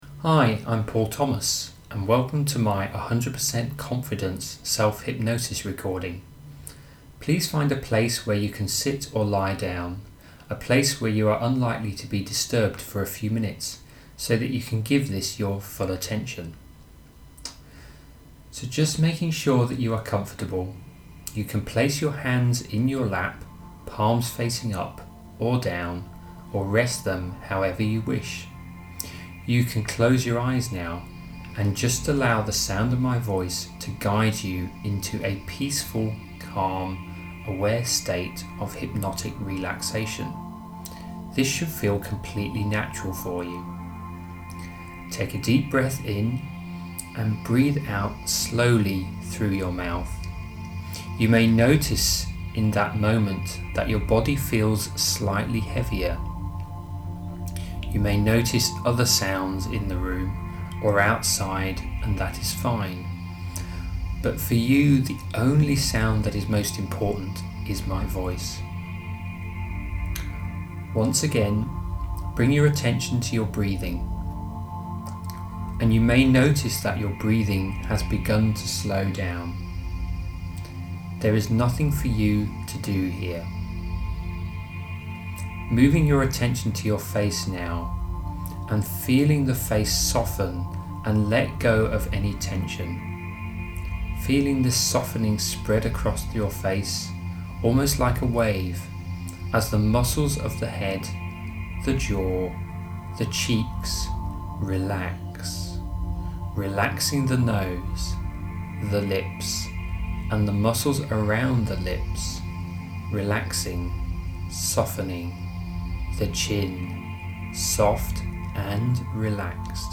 Click the button or image below to download your 100% Self-Confidence hypnosis recording
ConfidenceHypnosis.mp3